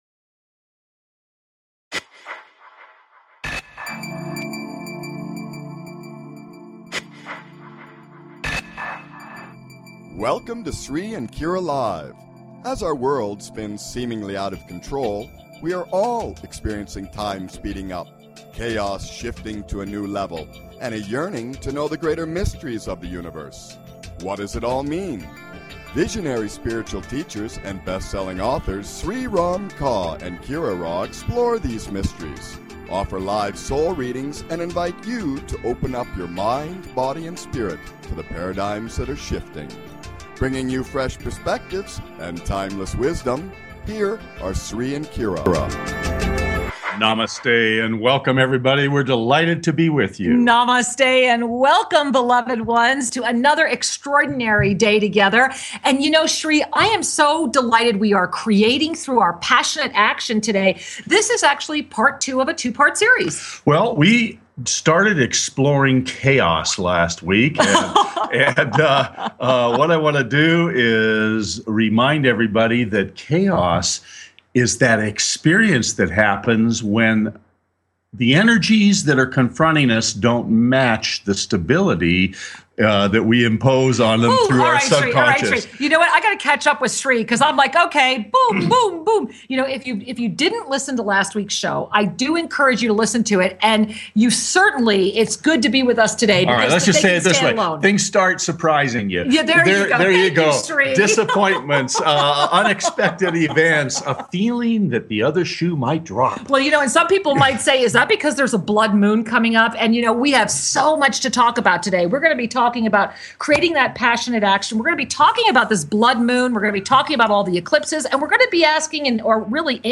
Talk Show Episode, Audio Podcast, CREATING THROUGH PASSIONATE ACTION!